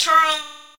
dancerTurn.wav